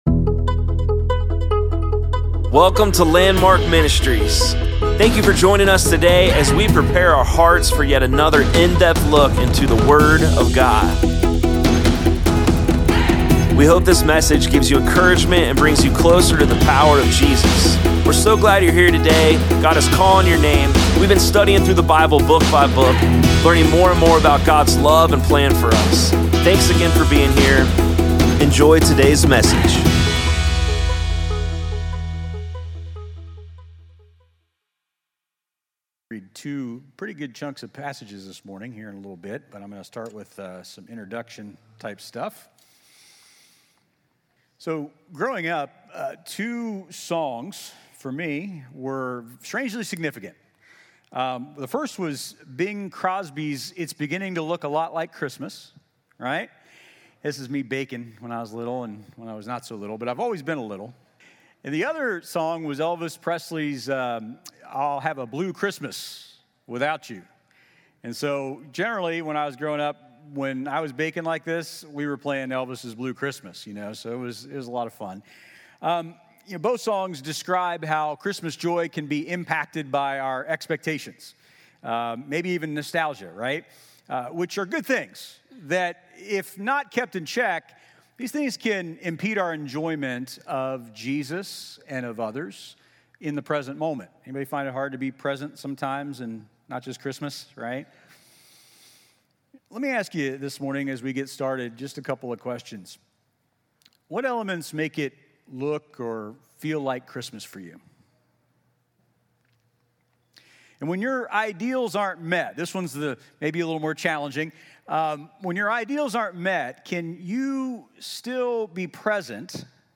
Sermons | Landmark Church of Clermont County, Ohio